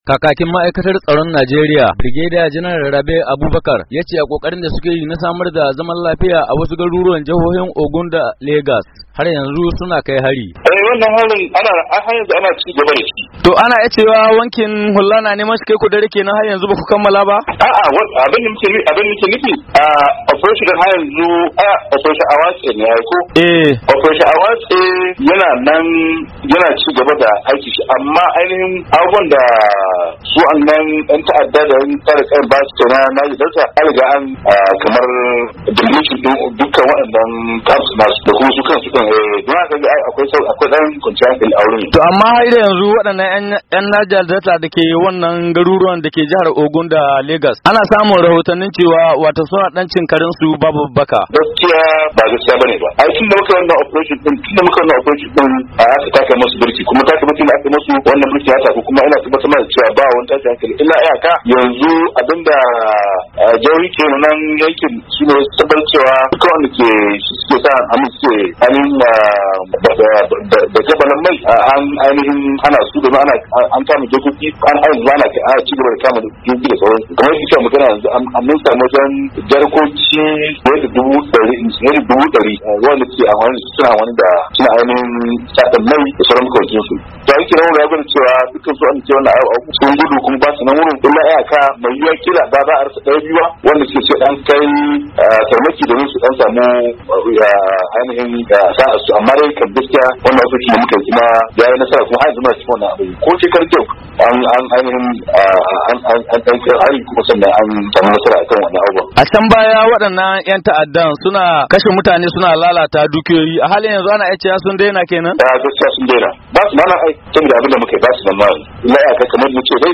a tattaunawarsa da wakilin Muryar Amurka